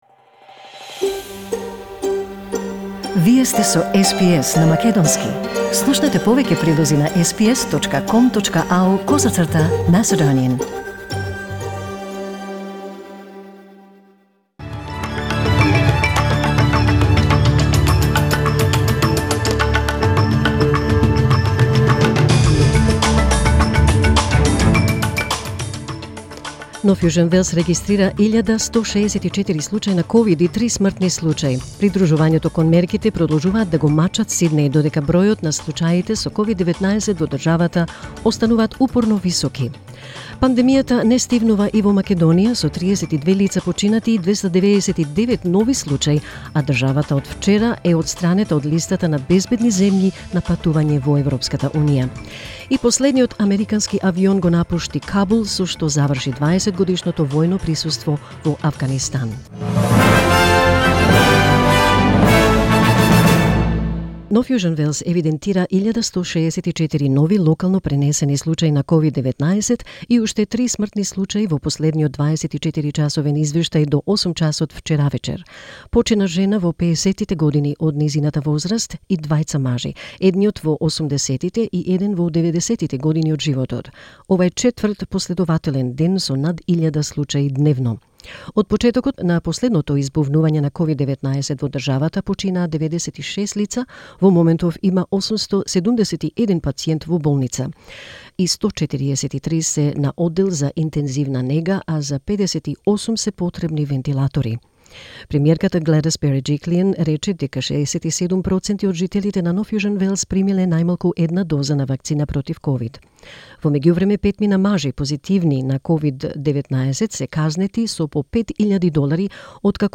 SBS News in Macedonian 31 August 2021